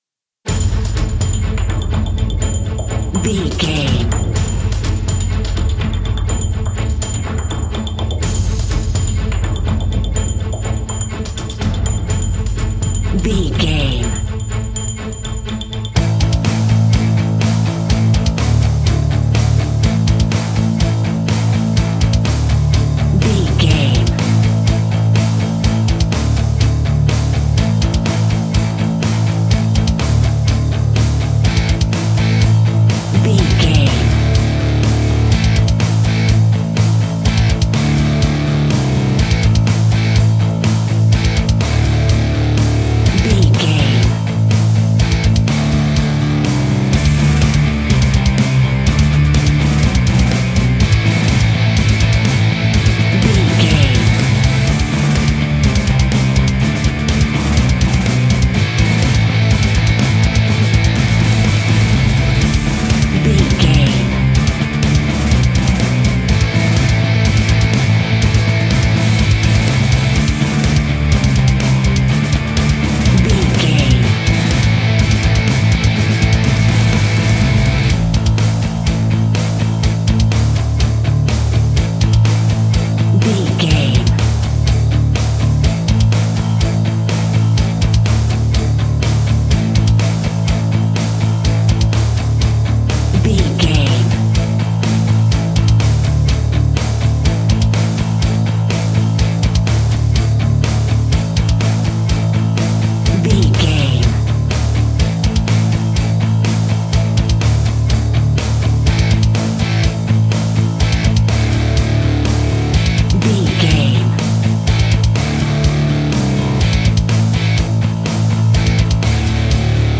Epic / Action
Fast paced
Aeolian/Minor
Fast
hard rock
Heavy Metal Guitars
Metal Drums
Heavy Bass Guitars